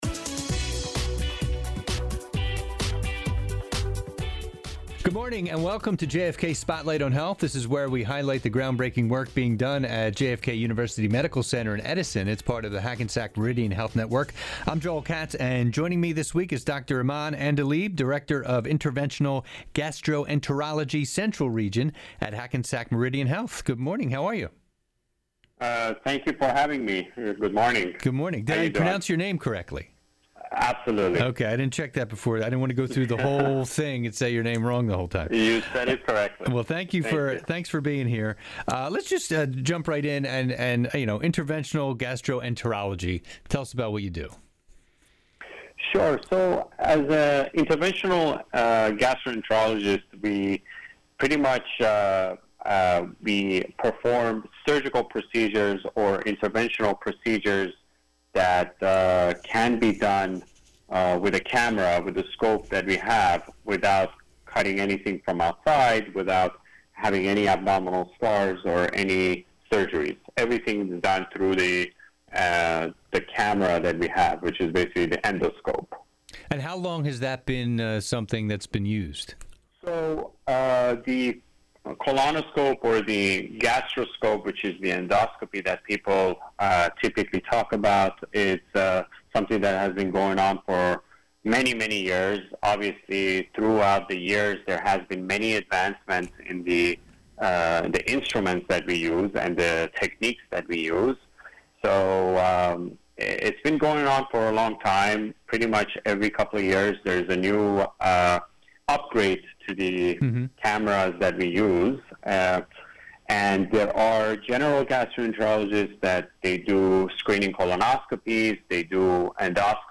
Each week, FOX Sports Radio NJ chats with medical professionals, surgical specialists, or program managers from Hackensack Meridian JFK University Medical